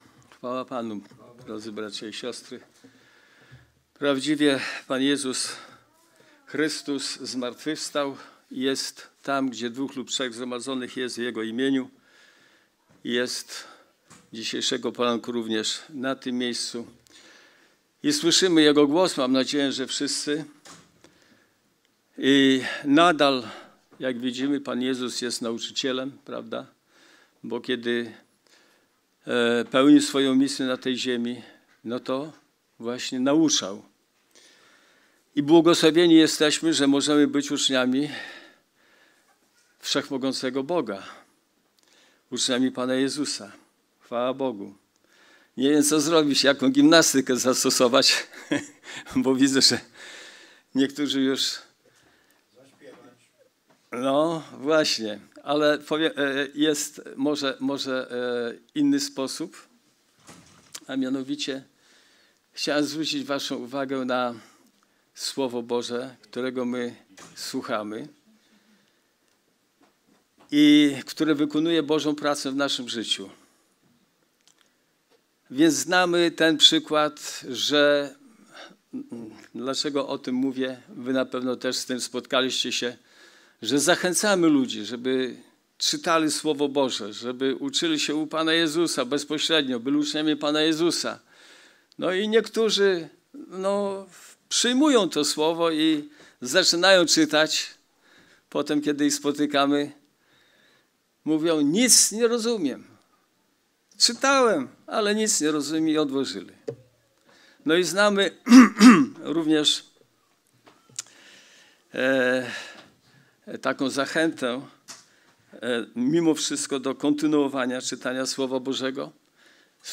Skorzystaj z przycisku poniżej, aby pobrać kazanie na swoje urządzenie i móc słuchać Słowa Bożego bez połączenia z internetem.